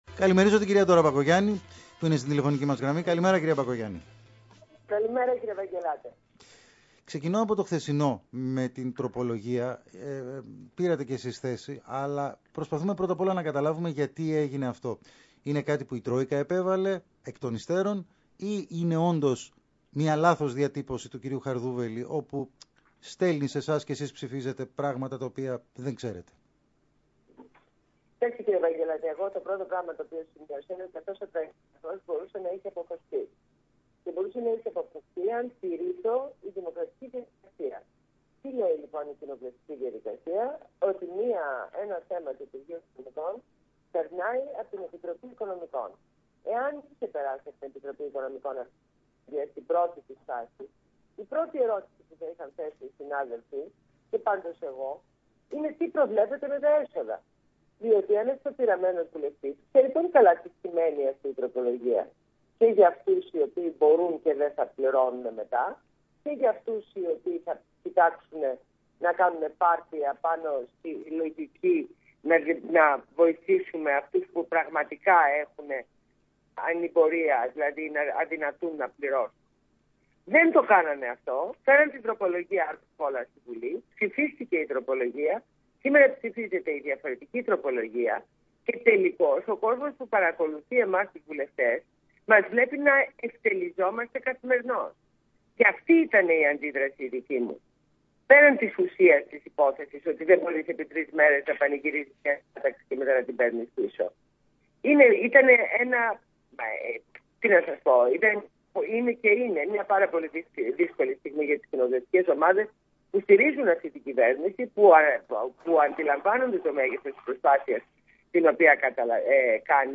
Συνέντευξη στο ραδιόφωνο Παραπολιτικά στον Ν. Ευαγγελάτο.